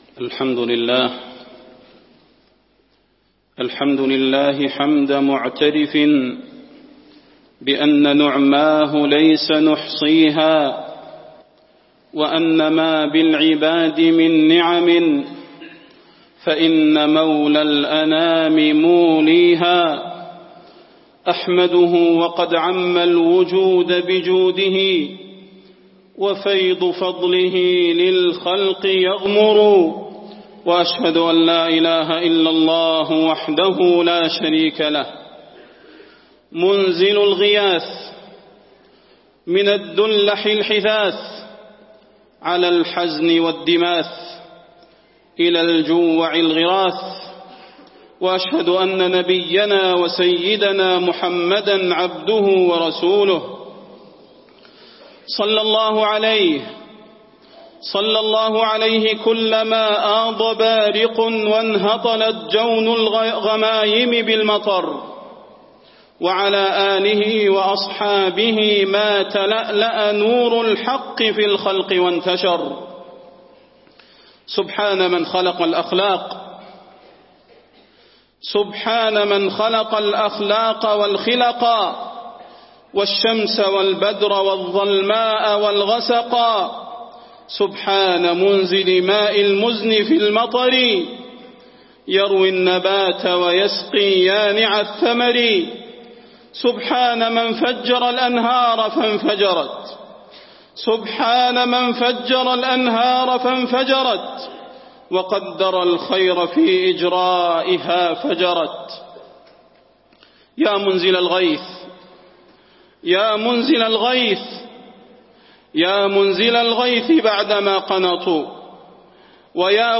خطبة الاستسقاء - المدينة- الشيخ صلاح البدير
تاريخ النشر ١٣ صفر ١٤٣٢ هـ المكان: المسجد النبوي الشيخ: فضيلة الشيخ د. صلاح بن محمد البدير فضيلة الشيخ د. صلاح بن محمد البدير خطبة الاستسقاء - المدينة- الشيخ صلاح البدير